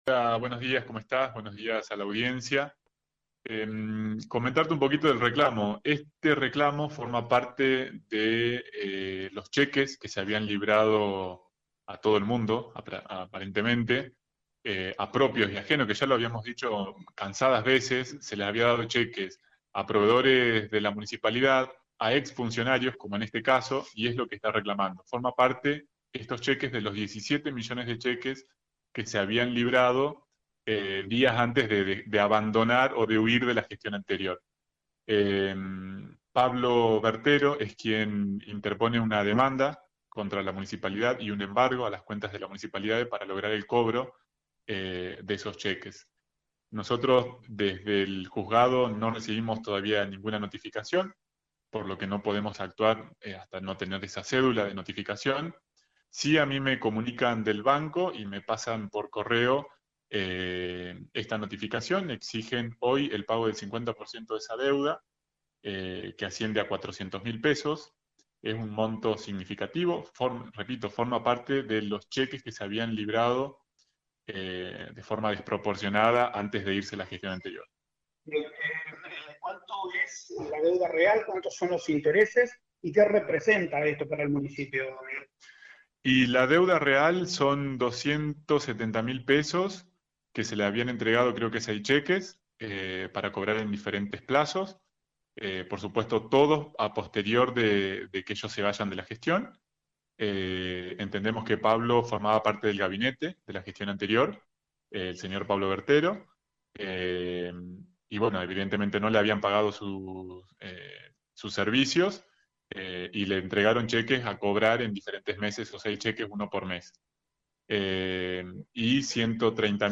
El Secretario de Hacienda CPN, Miguel Andrada habló sobre el embargo de una de las cuentas del Municipio.